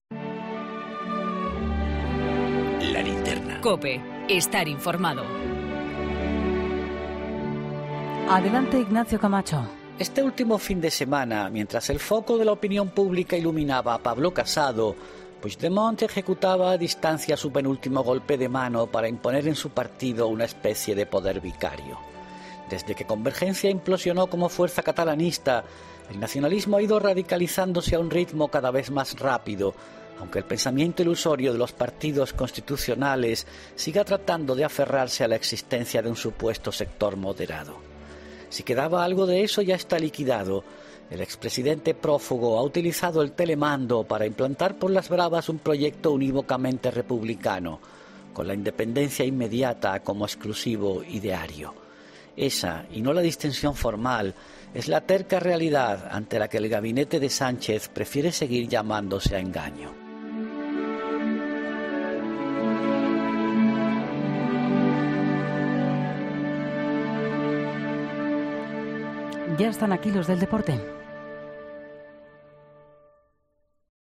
Ignacio Camacho habla en 'La Linterna' del mando de Puigdemont en el PDeCAT tras la asamblea celebrada este fin de semana.